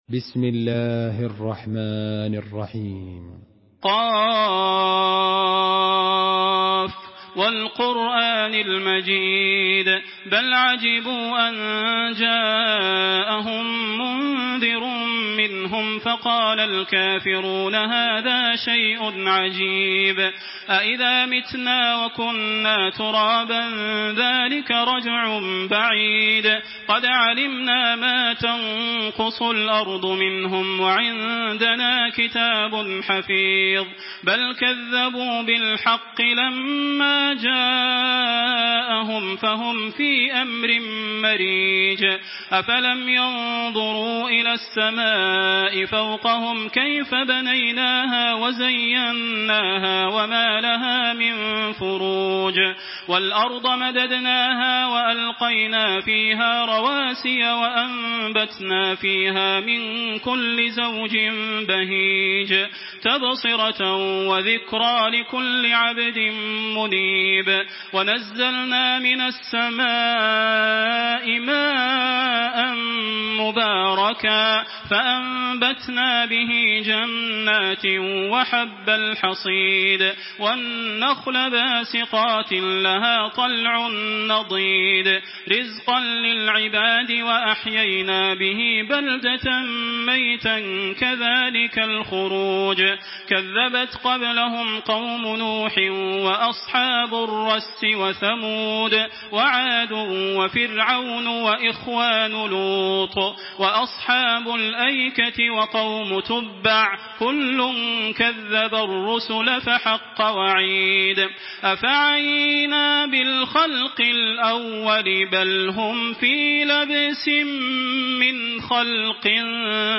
تحميل سورة ق بصوت تراويح الحرم المكي 1426
مرتل